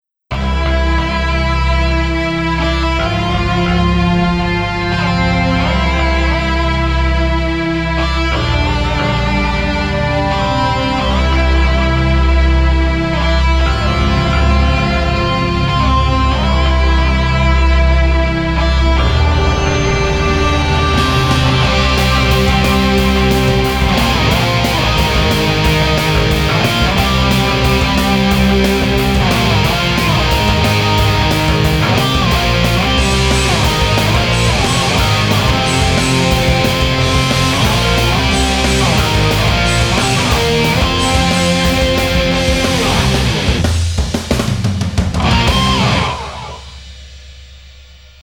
Guitar driven main theme